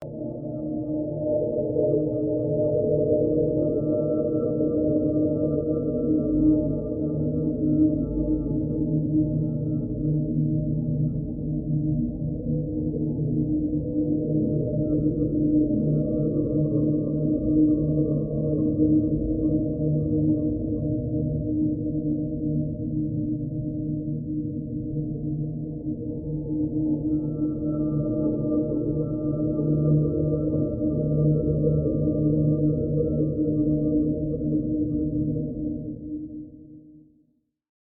Unknown Deep Ambiance
Unknown Deep Ambiance is a free ambient sound effect available for download in MP3 format.
Unknown Deep Ambiance.mp3